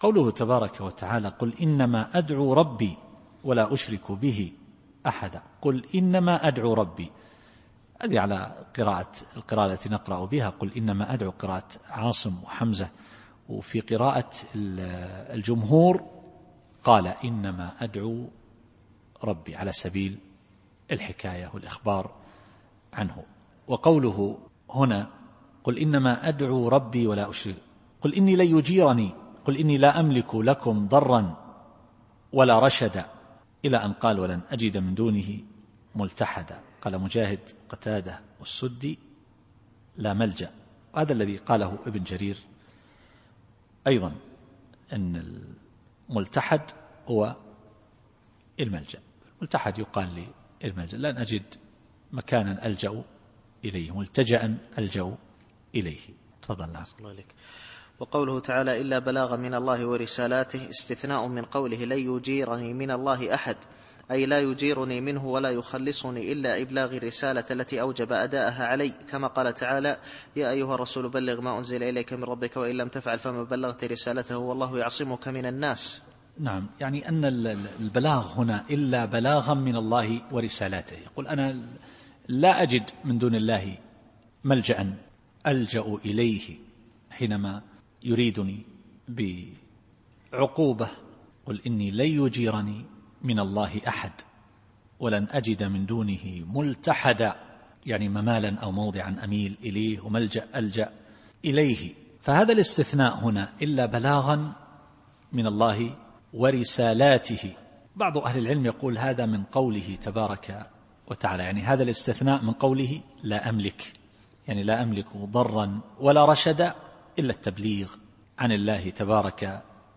التفسير الصوتي [الجن / 20]